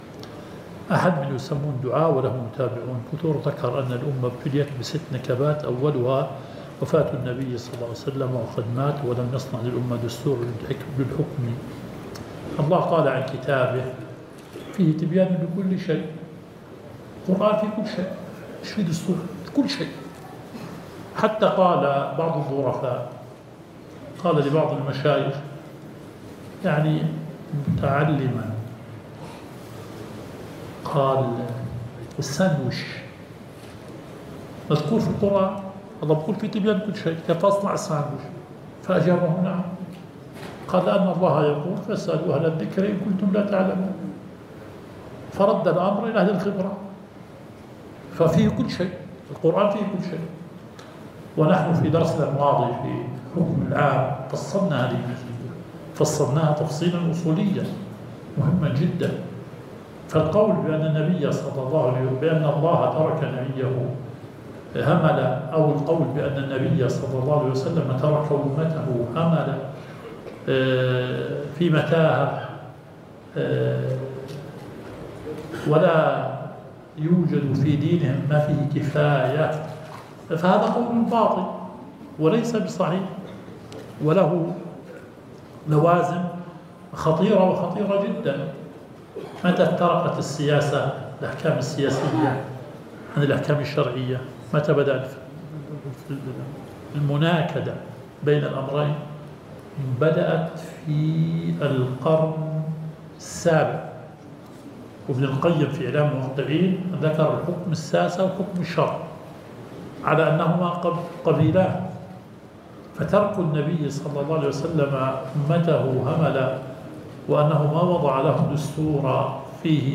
البث المباشر – لدرس شرح صحيح مسلم